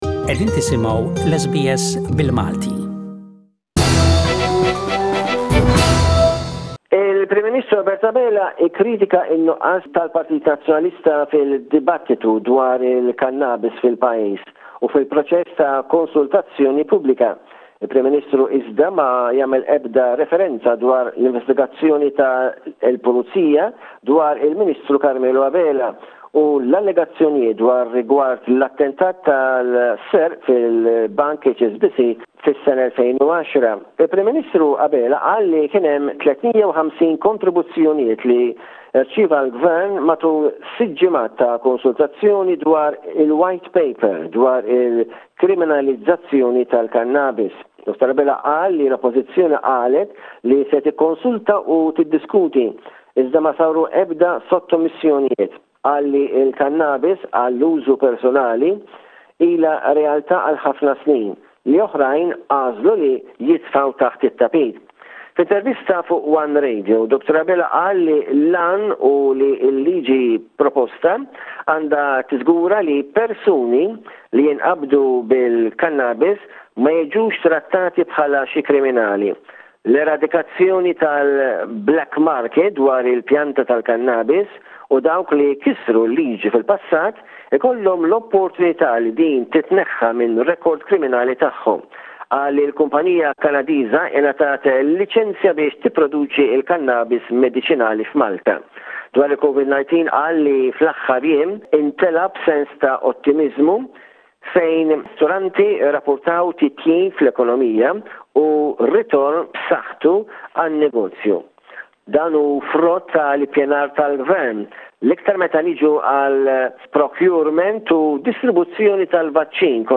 with this week's news report from Malta.